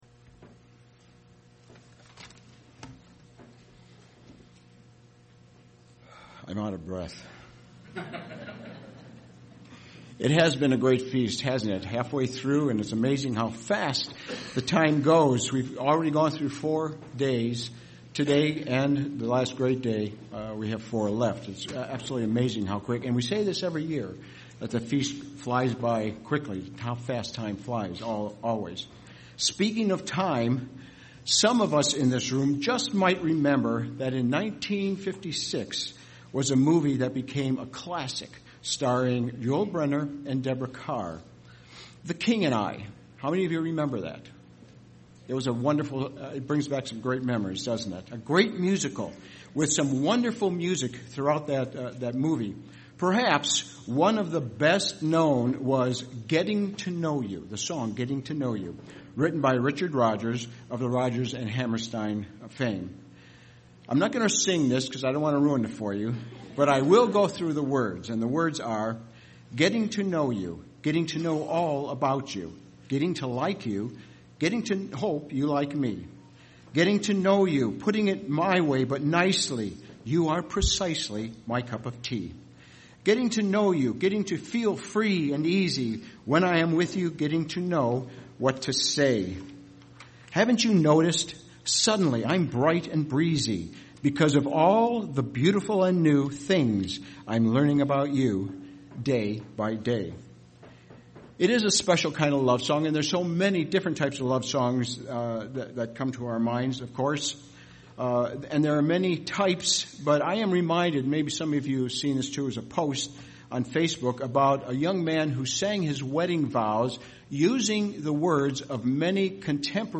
This sermon was given at the Cincinnati, Ohio 2018 Feast site.